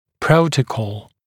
[‘prəutəkɔl][‘проутэкол]протокол